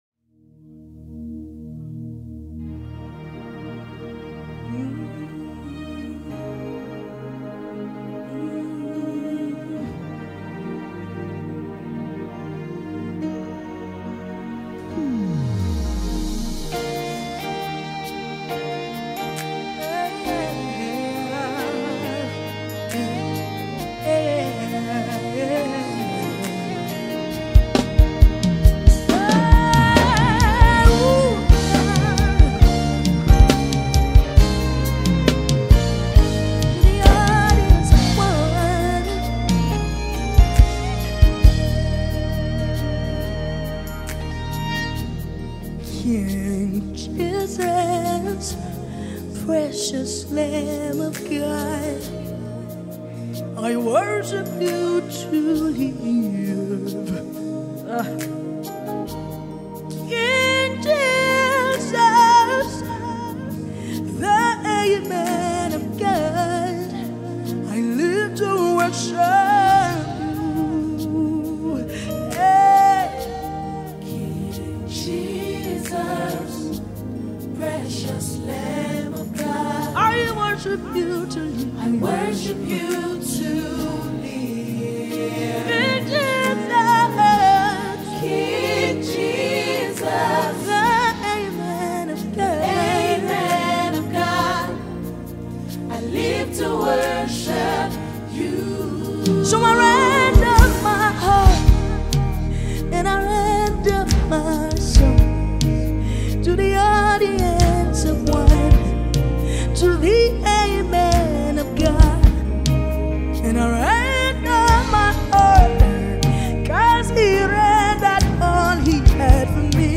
gigantic worship melody
Nigerian contemporary gospel music